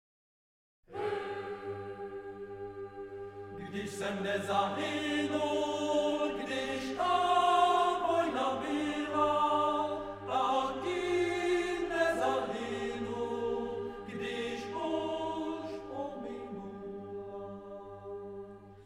natočeno v červnu 1997 ve studiu Domovina v Praze
Česká sborová tvorba na texty lidové poezie